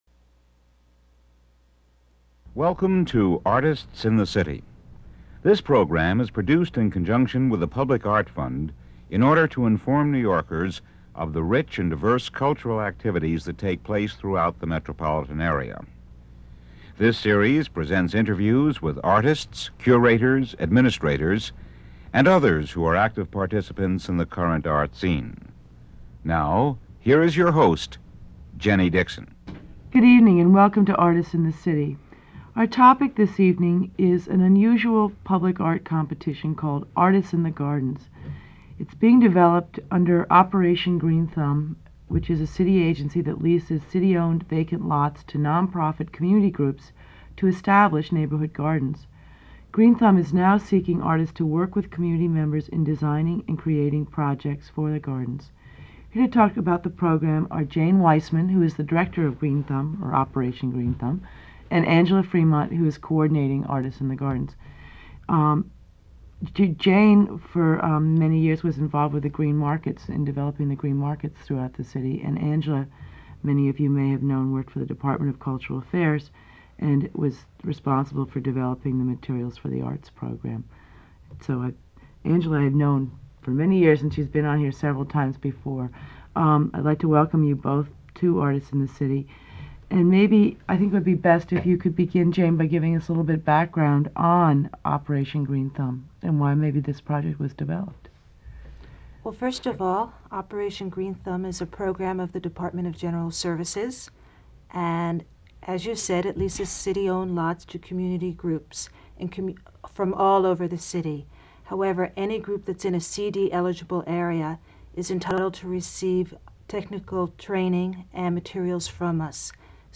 Operation Green Thumb Interview
Green-thumb-interview.mp3